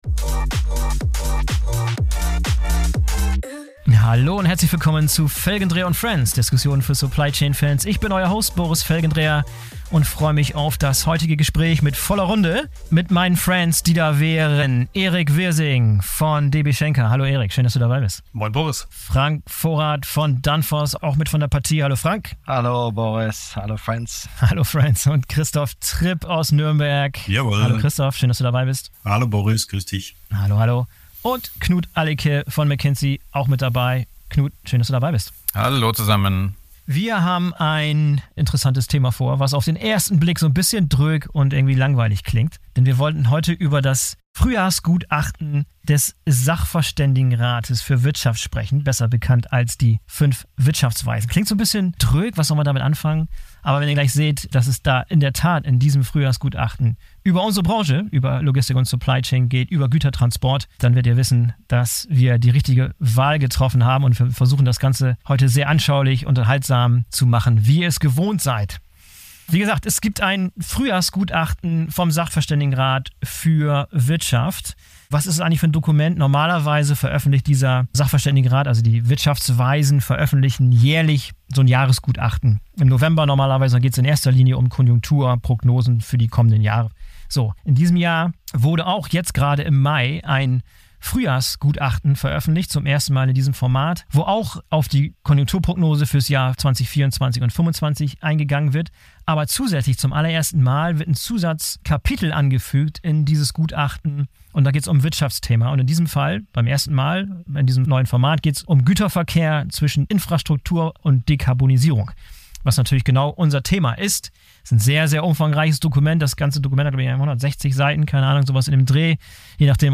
Wir haben uns dieses Gutachten mal näher angeschaut und diskutieren die Erkenntnisse in unserer Runde von Logistik und Supply Chain Experten. Unter anderem geht es dabei um den Zustand der Straßen- und Schieneninfrastruktur in Deutschland, die Verlagerung von Verkehren von der Straße auf die Schiene und die Dekarbonisierung des Güterverkehrs mit Hilfe von alternativen Antrieben.